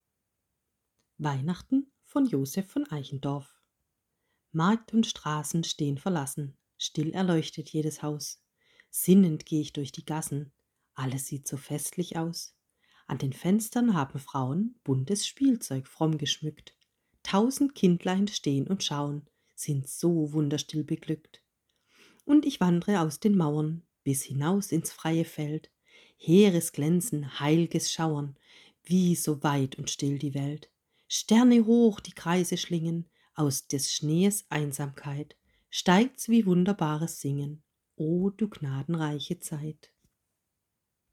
liest eine Geschichte aus Norwegen